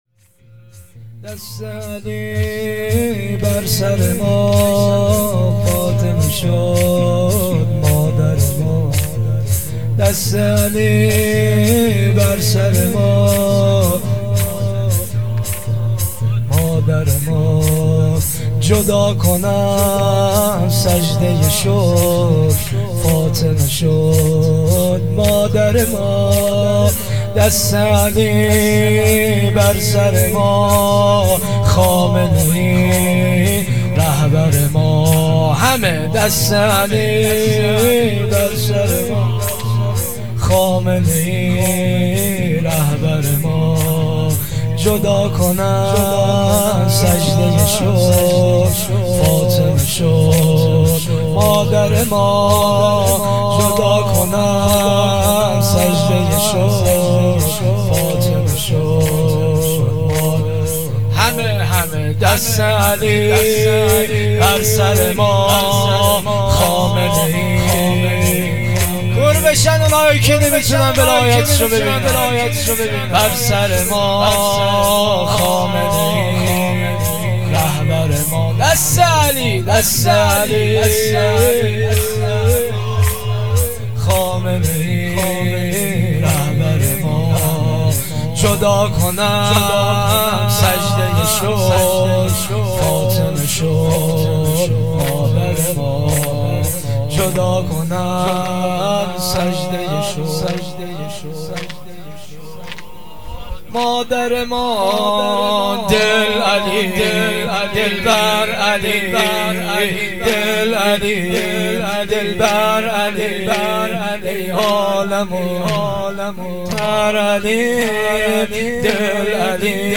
مراسم هفتگی